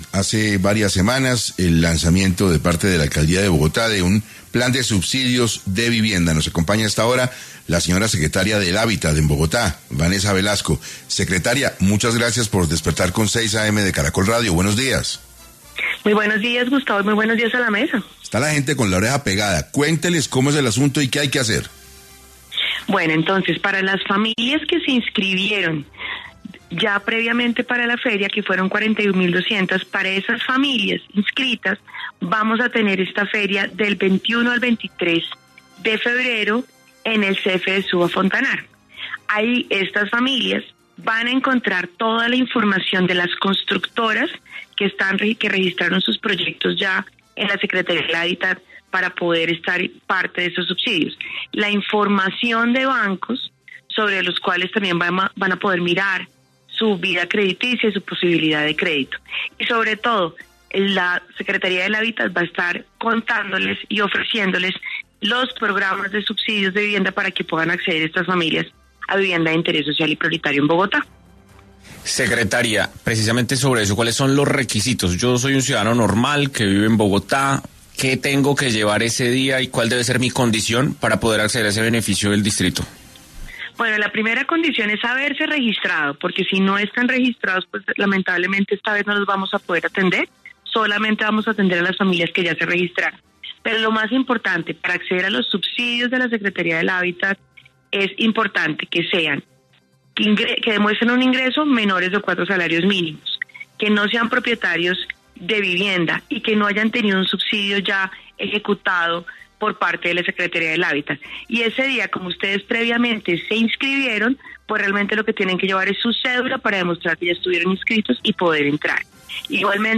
En esta campaña se inscribieron más de 41.200 familias; en entrevista para 6AM Vanessa Velasco, secretaria de Hábitat de Bogotá, nos contó los pasos a seguir para estas familias.